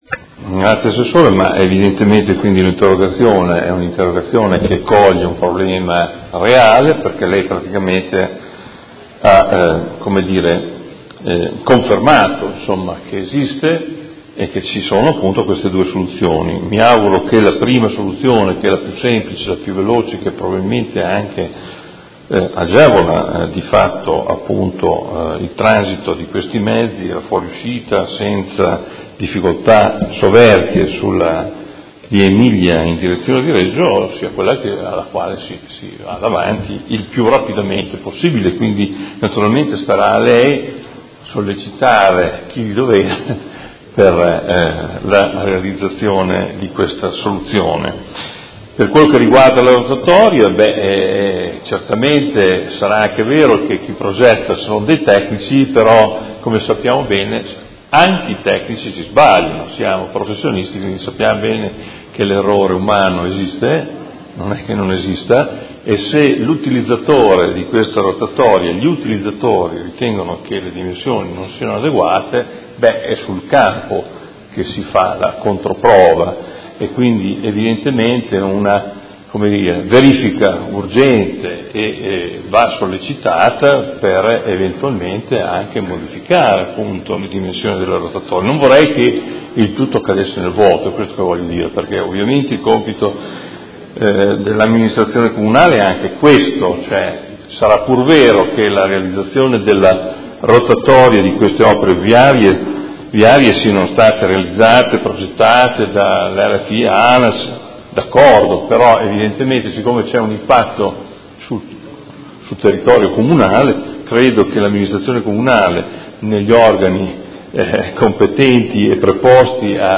Seduta del 13/07/2017 Replica a risposta Assessore. Interrogazione del Consigliere Morandi (FI) avente per oggetto: La nuova viabilità della Via Emilia a Cittanova con la creazione della rotatoria necessaria all’accesso per il nuovo scalo ferroviario, crea notevoli disagi ai residenti di Cittanova a sud della Via Emilia